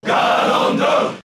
Ganondorf_Cheer_Japanese_SSBB.ogg